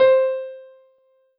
piano-ff-52.wav